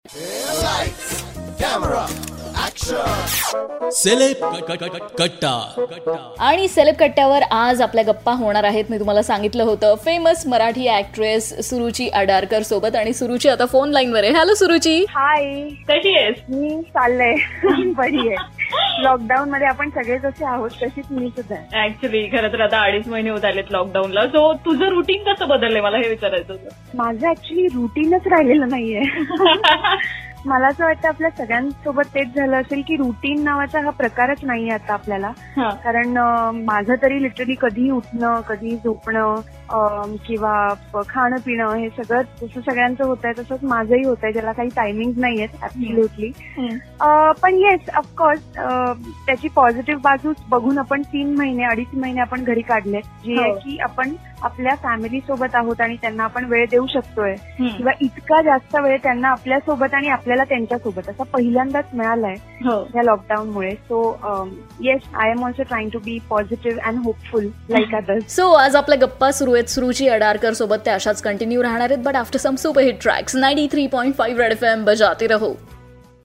In this interview she shared her lockdown routine..